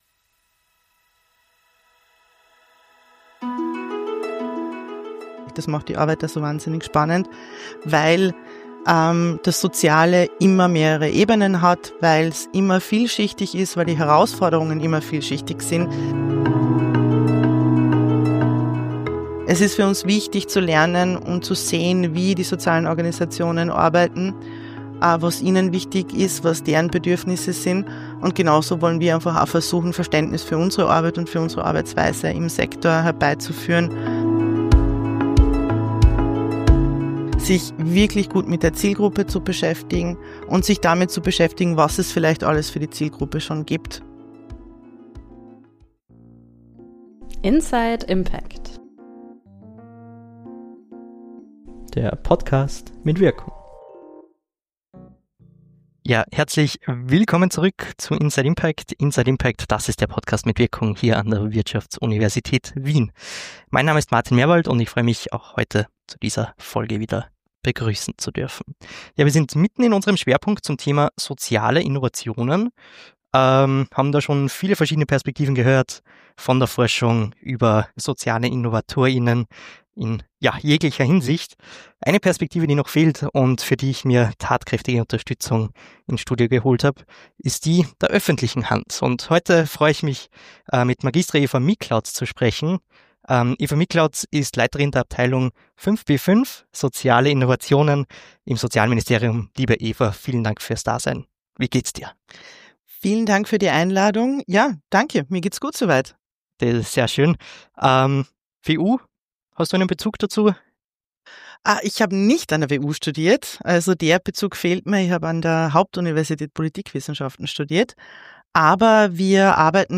Außerdem geht es um die Frage, wie mit Risiken in Verbindung mit Innovation und dem Zusammenspiel Social Start-Up - Ministerium in der Praxis aussieht. Ein Gespräch über Herausforderungen, Potenziale und internationale Perspektiven.